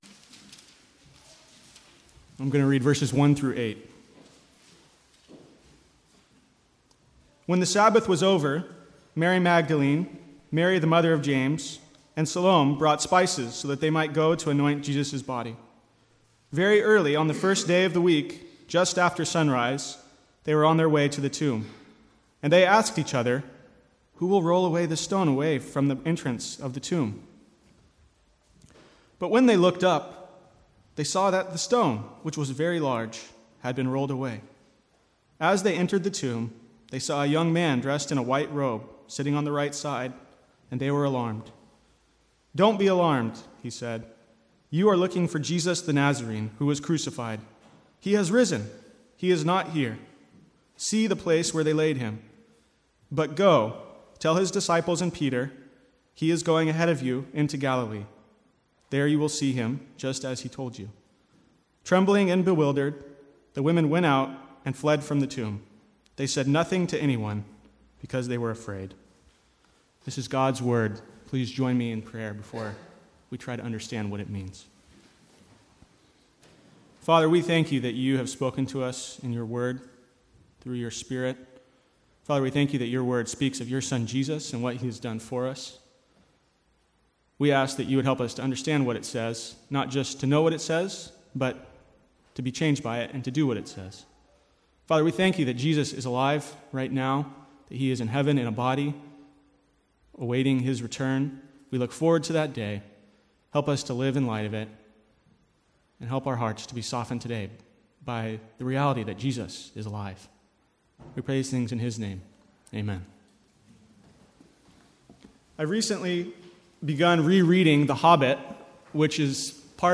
From the Easter Sunday morning service 2014.